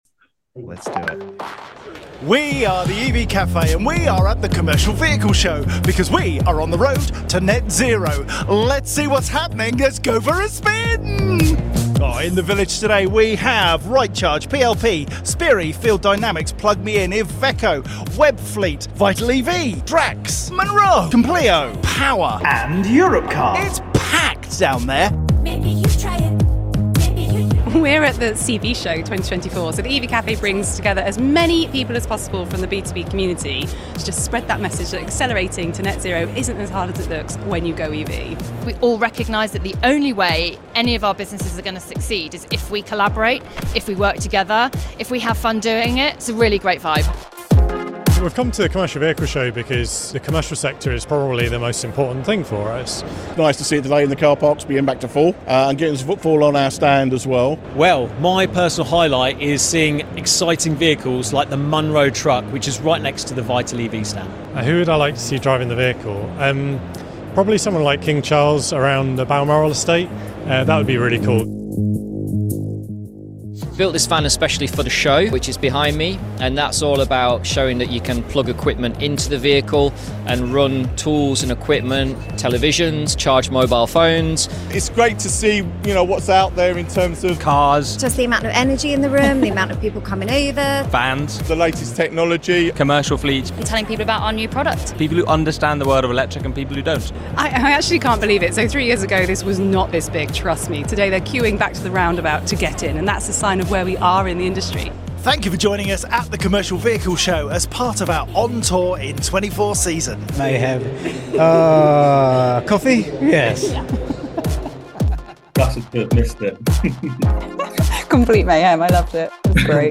‘Vans, Trucks, and EV Lovin’ was our May 2024 webinar